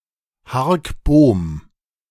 Hark Bohm (German: [haʁk boːm]